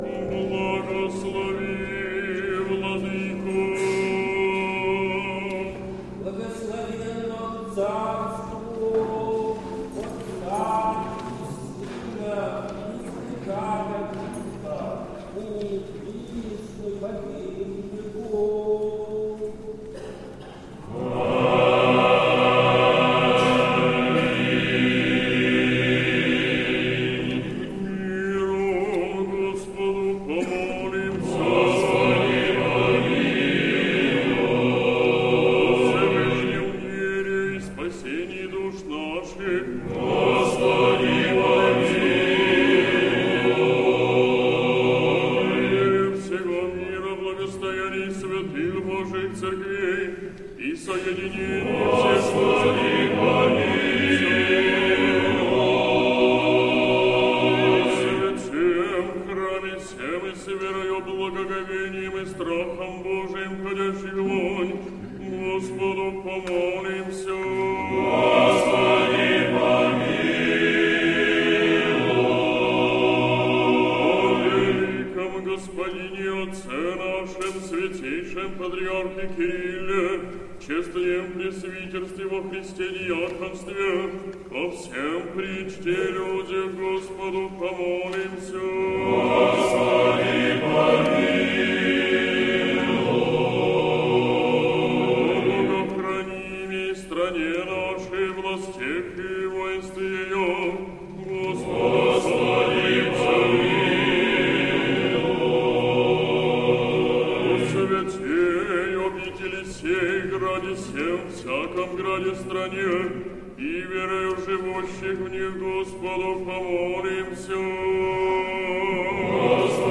Сретенский монастырь. Божественная литургия. Хор Сретенского монастыря.
Божественная литургия в день Вознесения Господня в Сретенском монастыре